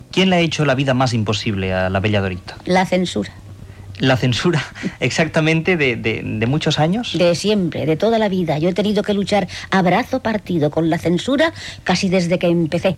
Entrevista a la vedet Bella Dorita (María Yáñez García).